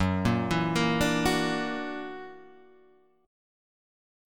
F# Augmented 7th